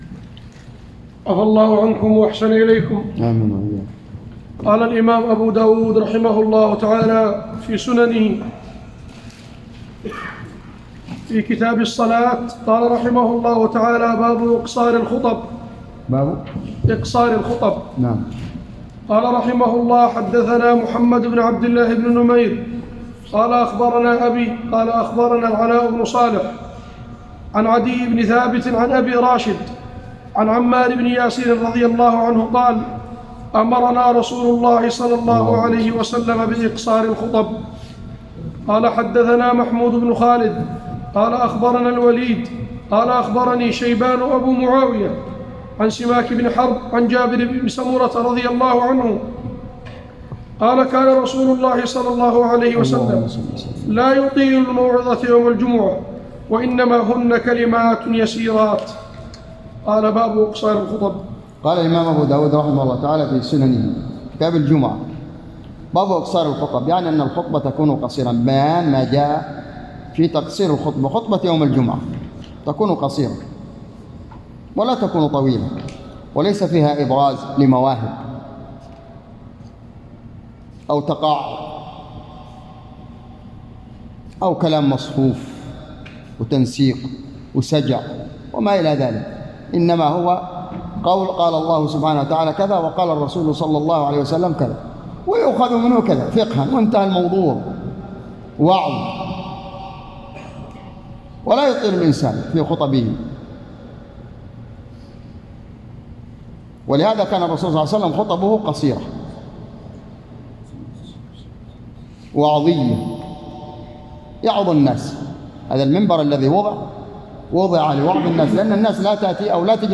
تسجيل لدروس شرح كتاب الجمعة - سنن أبي داود  _ بجامع الدرسي بصبيا